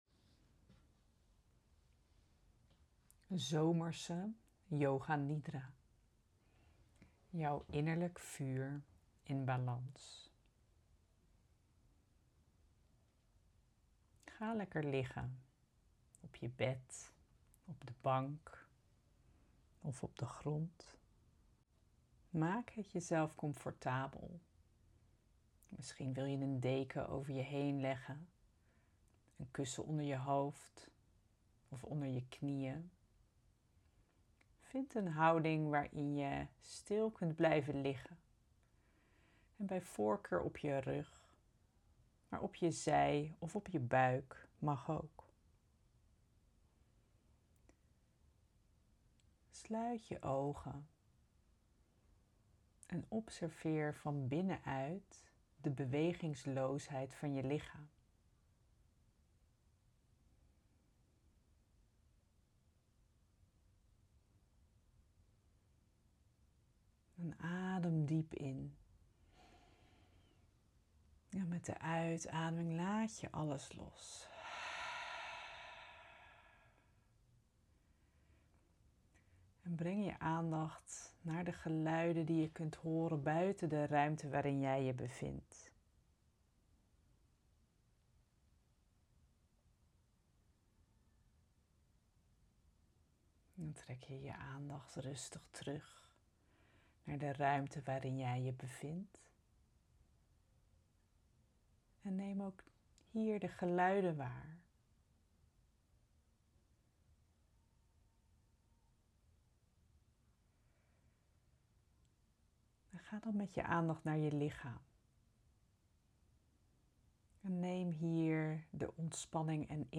Yoga Nidra, ook wel de 'yogische slaap' genoemd, is een krachtige vorm van begeleide meditatie waarbij je ligt en niets hoeft te doen – behalve luisteren.